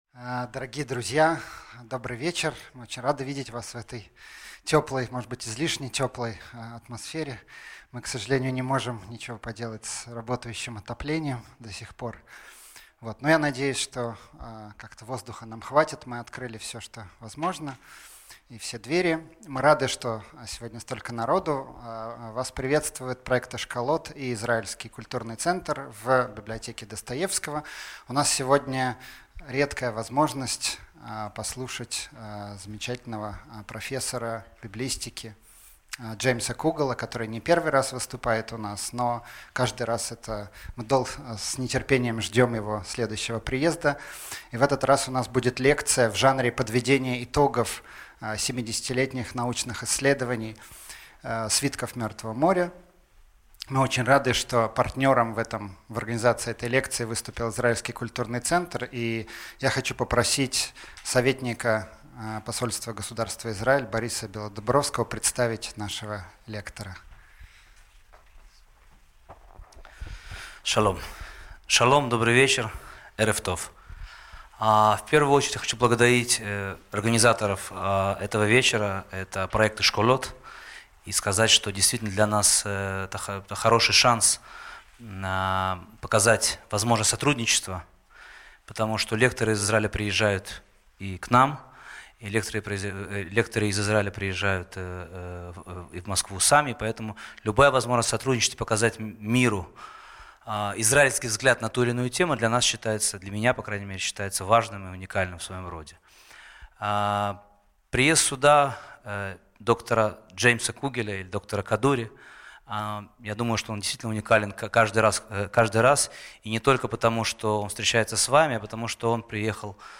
Аудиокнига Ожившие свитки Мертвого моря | Библиотека аудиокниг